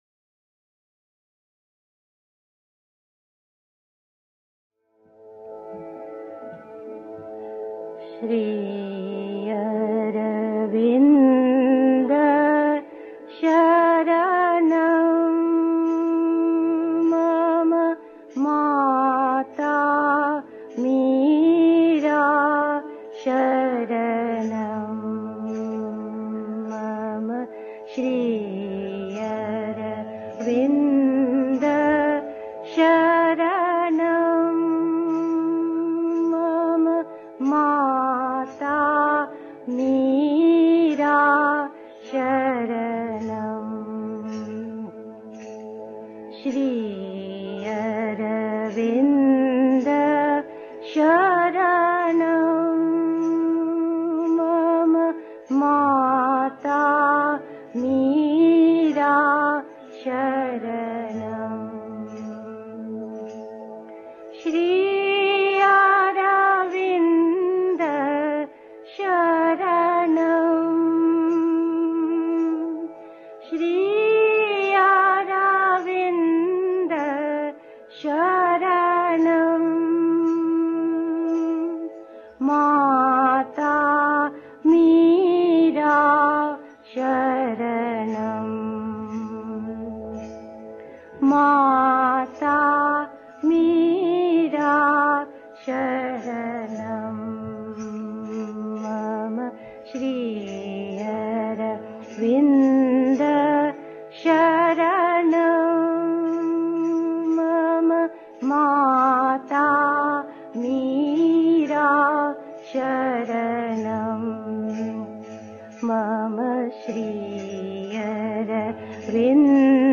1. Einstimmung mit Musik. 2. Glaube und Zweifel (Sri Aurobindo, CWSA, Vol. 23, pp. 774-76) 3. Zwölf Minuten Stille.